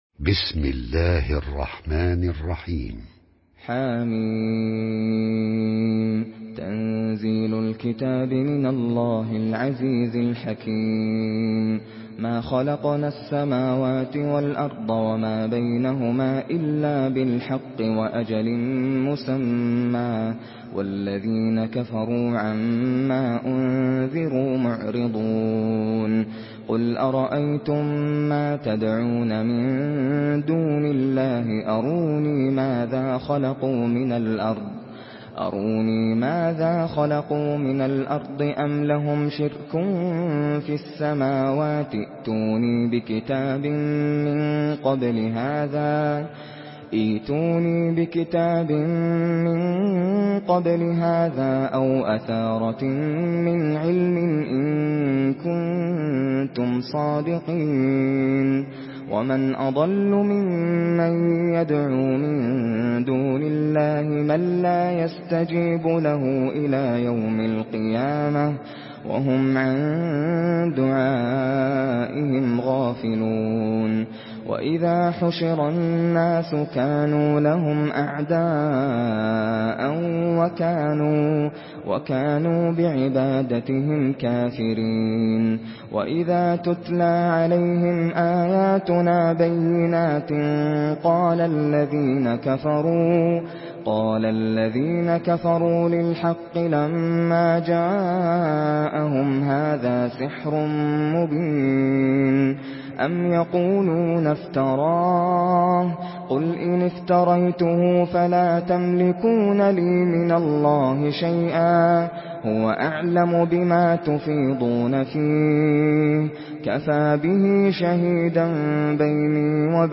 Surah الأحقاف MP3 by ناصر القطامي in حفص عن عاصم narration. Listen and download the full recitation in MP3 format via direct and fast links in multiple qualities to your mobile phone.